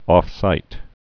(ôfsīt, ŏf-)